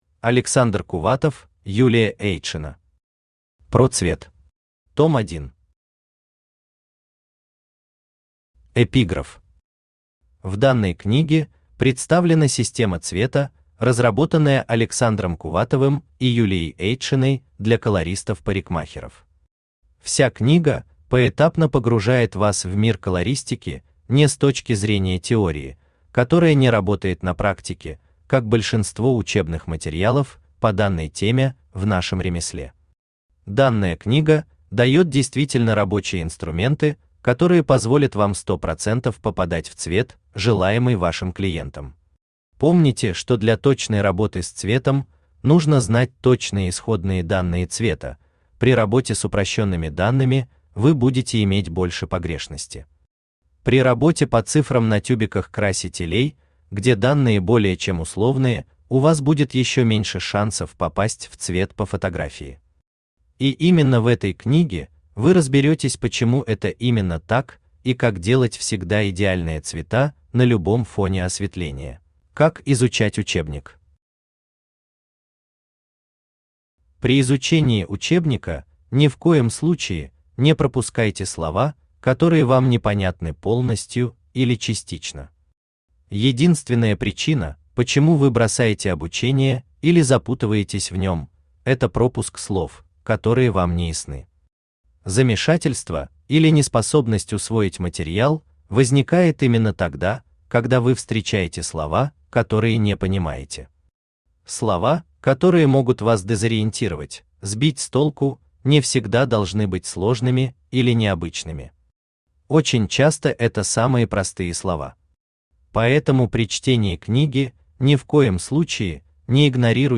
Аудиокнига PRO цвет. Том 1 | Библиотека аудиокниг
Том 1 Автор Александр Сергеевич Кувватов Читает аудиокнигу Авточтец ЛитРес.